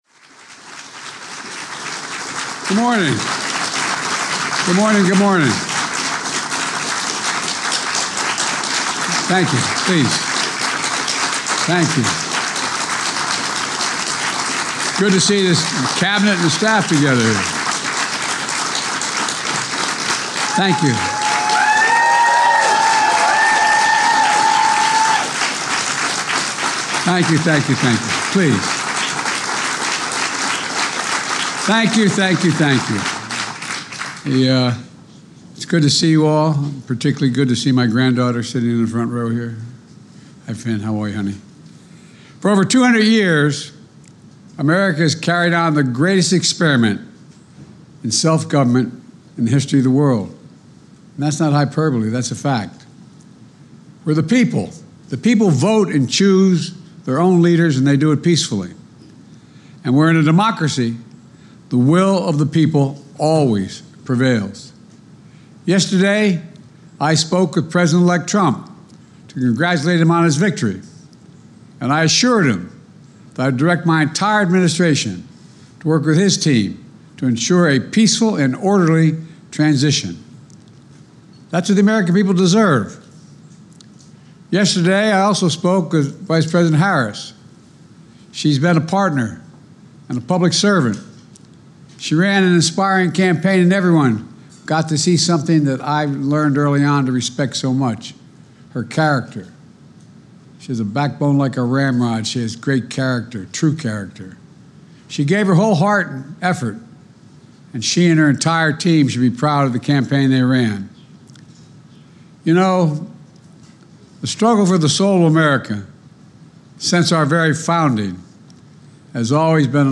Address to the Nation on the 2024 Presidential Election Outcome
delivered 7 November 2024, Rose Garden, White House, Washington, D.C.
Audio Note: AR-XE = American Rhetoric Extreme Enhancement.